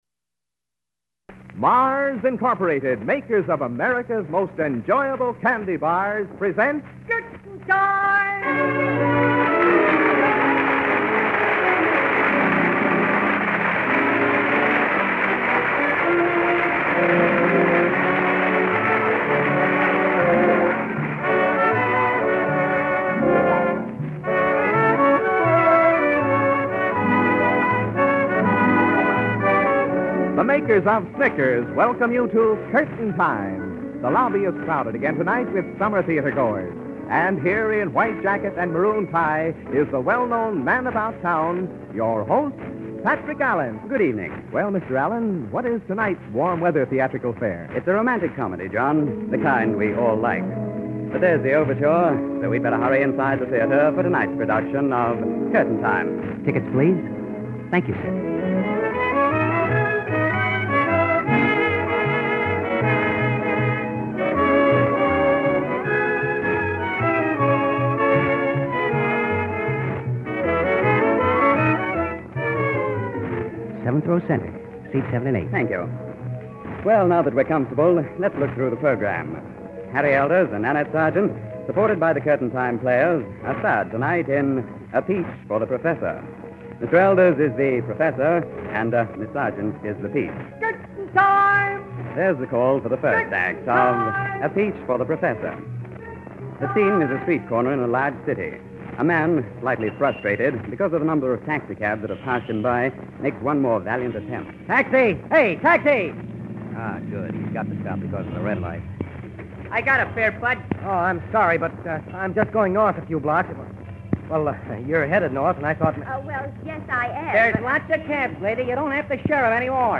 Curtain Time was a popular American radio anthology program that aired during the Golden Age of Radio.
Format and Features: "Theater Atmosphere": The show used sound effects and announcements to evoke the feeling of being in a theater, with an announcer acting as an usher and reminding listeners to have their tickets ready. Romantic Dramas: Each episode featured a different romantic story, often with a "boy meets girl" theme.